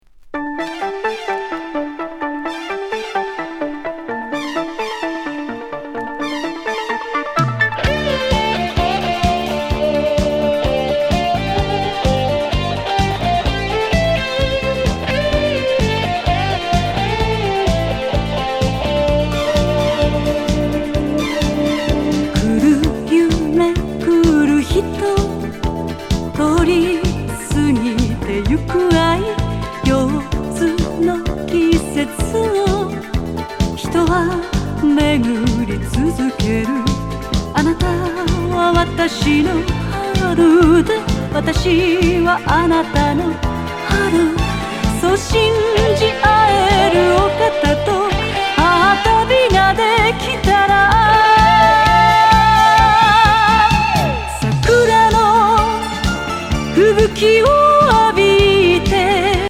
哀愁シンセ・ディスコ！